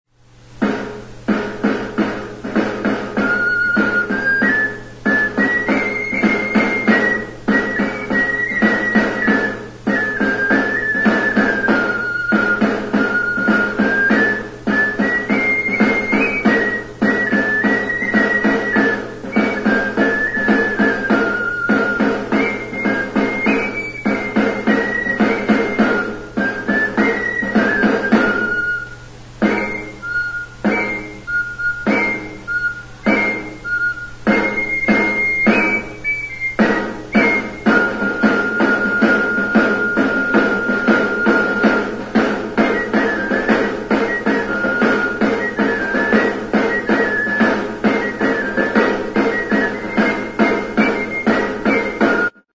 Jardunaldiak.
GAITA DE ALA DE BUITRE
Aerophones -> Flutes -> Fipple flutes (one-handed)
Hezurrez egindako hiru zuloko flauta da.